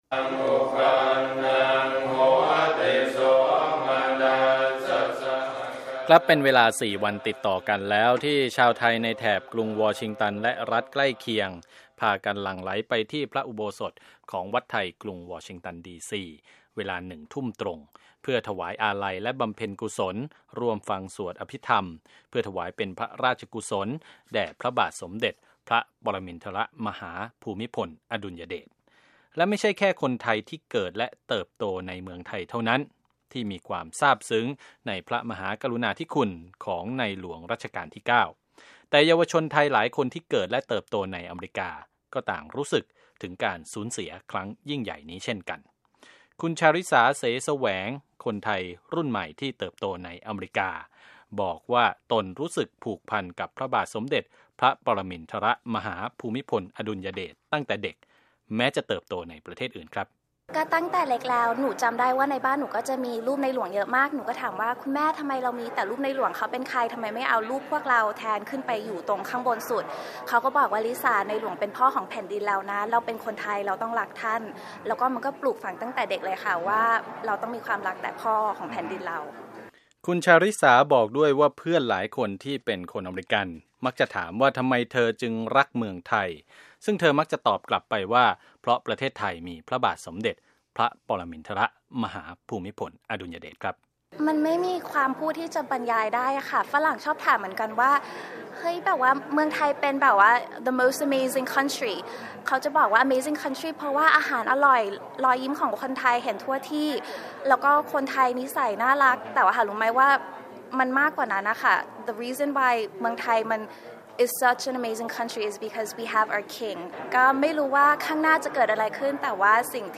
Thai King Royal Funeral at Wat Thai DC
พิธีบำเพ็ญกุศลสวดอภิธรรม เพื่อถวายเป็นพระราชกุศลแด่พระบาทสมเด็จพระปรมินทรมหาภูมิพลอดุลยเดช ณ อุโบสถวัดไทยกรุงวอชิงตัน ดี.ซี. จะมีต่อเนื่องไปจนถึงวันที่ 12 พฤศจิกายน